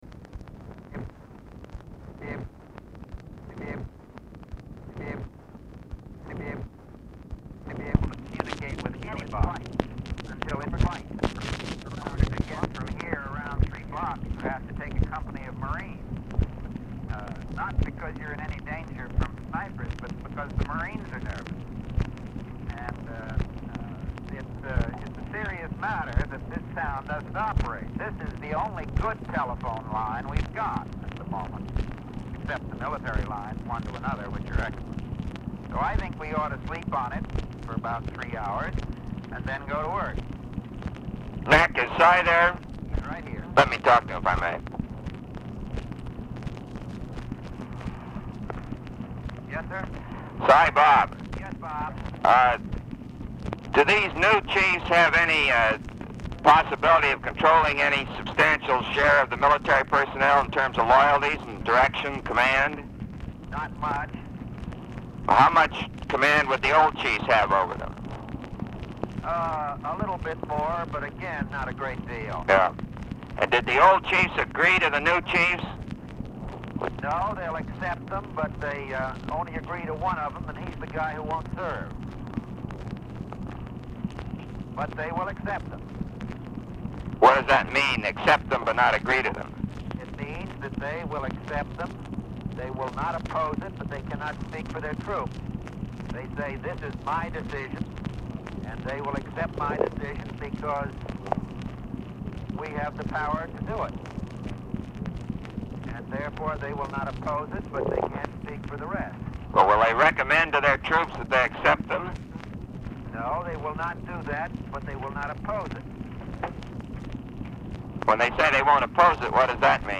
BUNDY AND VANCE ARE IN DOMINICAN REPUBLIC AND ARE DIFFICULT TO HEAR
Format Dictation belt
Specific Item Type Telephone conversation